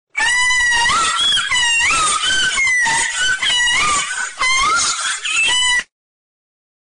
ahh sound sound effects free download